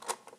rewind dn.aiff